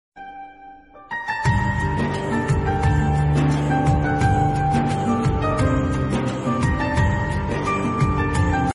vairal ringtone sound sound effects free download